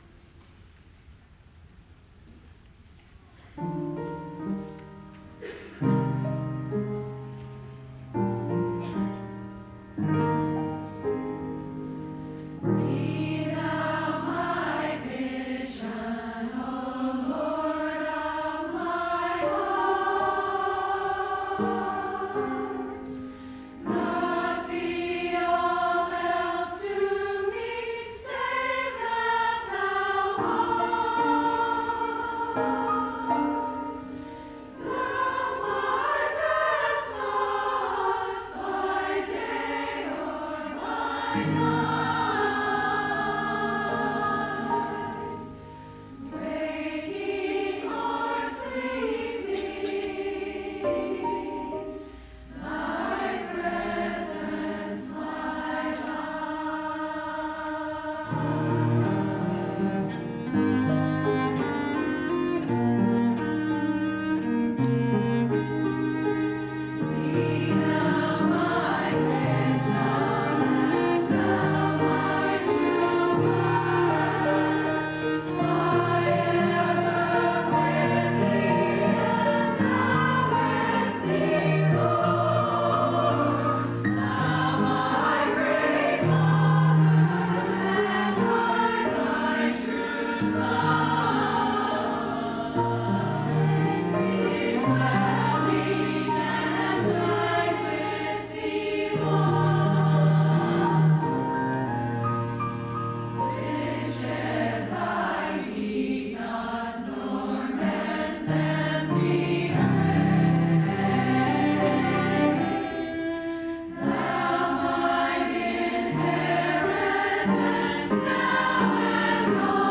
“Be Thou My Vision” ~ by Ladies and Girls Choir on Mother’s Day
be-thou-my-vision-ladies-and-girls-choir-on-mothers-day.mp3